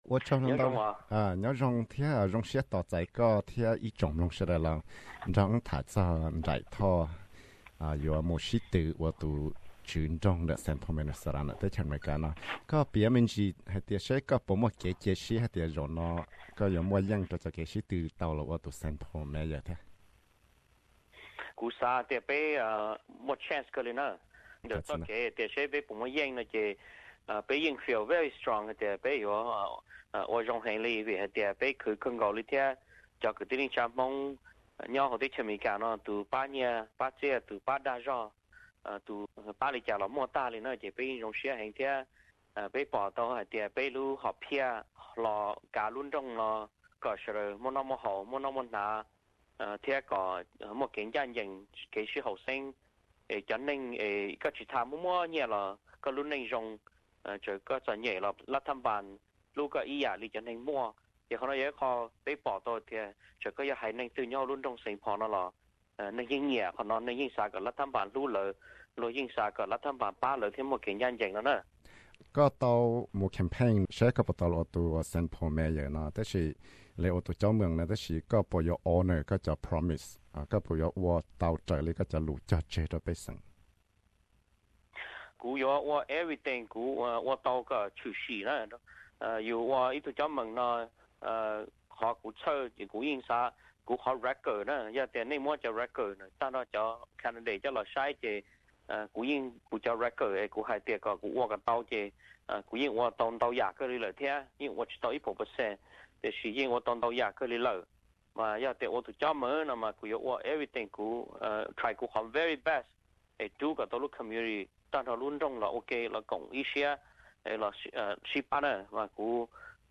Council member Dai Thao campaign for St Paul Minnesota, USA Source: Courtesy of Dai Thao for St Paul Minnesota mayor